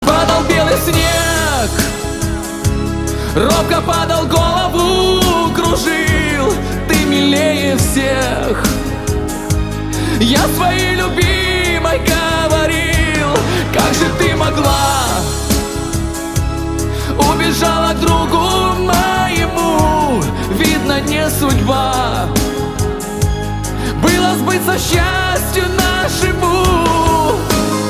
• Качество: 128, Stereo
грустные
Отличный рингтон в стиле шансон